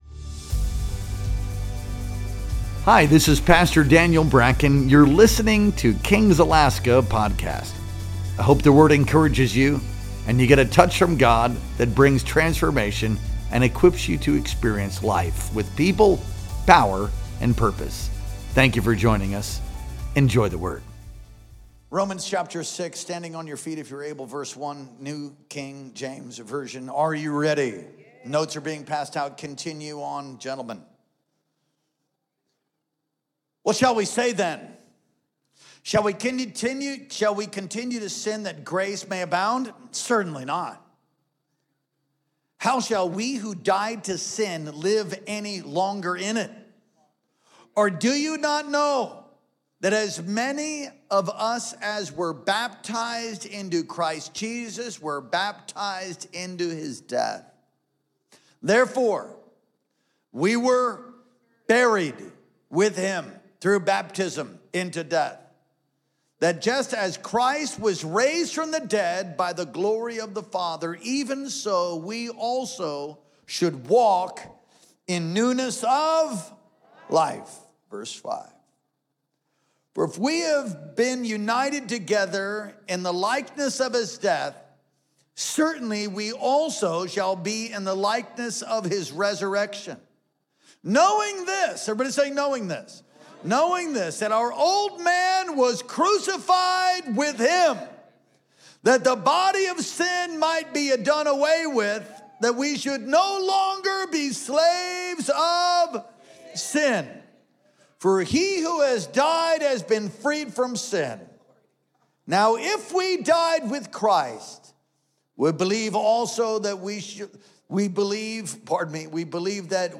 Our Sunday Night Worship Experience streamed live on April 27th, 2025.